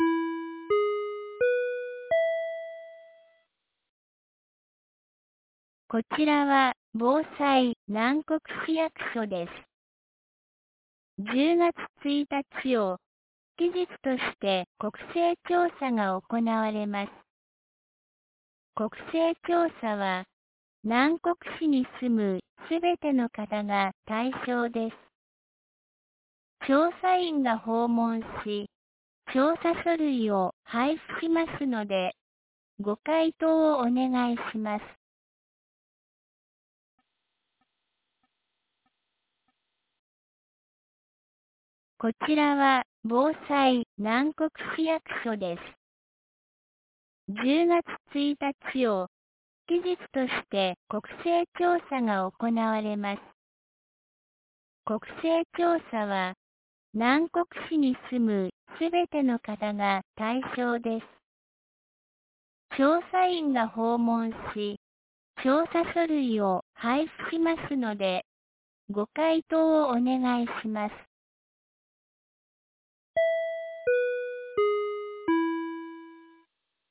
2025年09月20日 10時01分に、南国市より放送がありました。